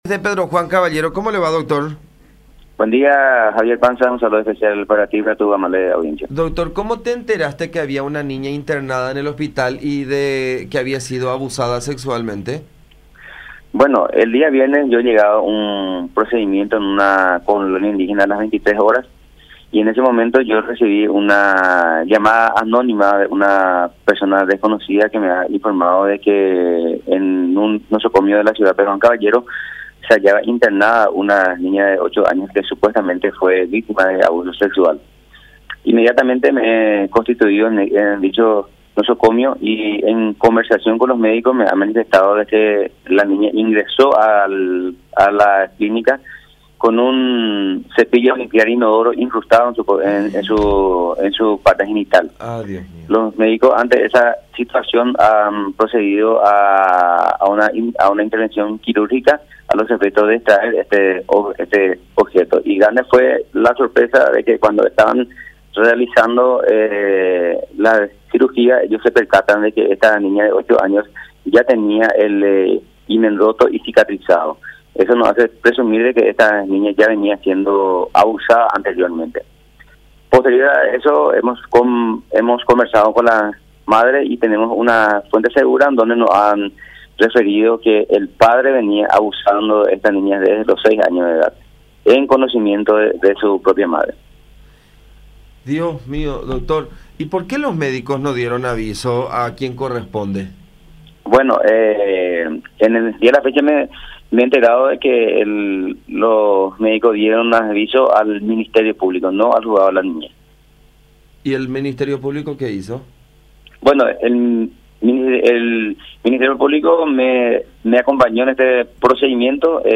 Así lo informó el Dr. Elvio Insfrán, Juez de la Niñez de Pedro Juan Caballero, quien tomó conocimiento del caso tras recibir una llamada anónima el pasado viernes.
07-ELVIO-INSFRÁN-JUEZ.mp3